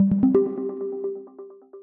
pause-retry-click.wav